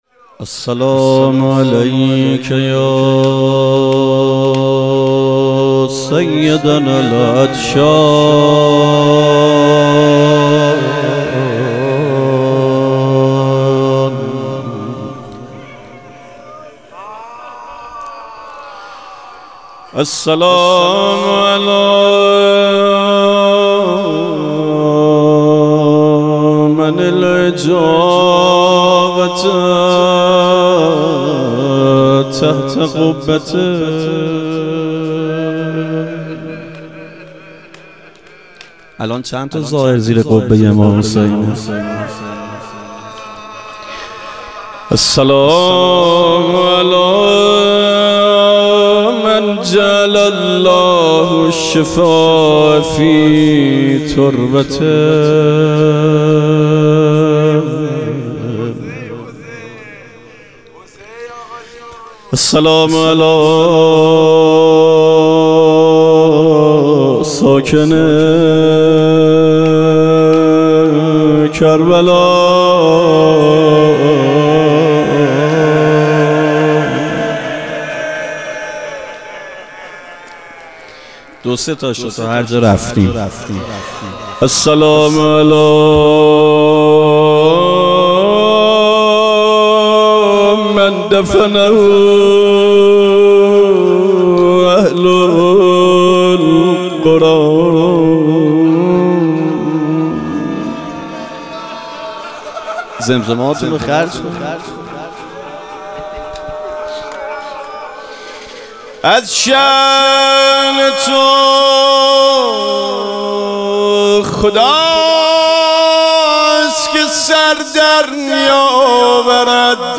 ظهور وجود مقدس حضرت زینب علیها سلام - روضه